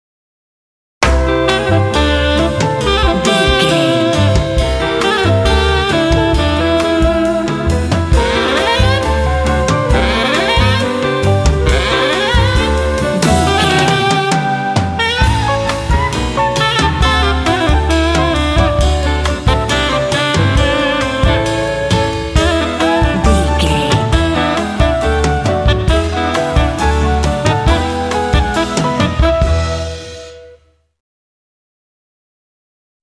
Aeolian/Minor
groovy
smooth
cheerful/happy
sensual
saxophone
flute
drums
double bass
piano
jazz
bossa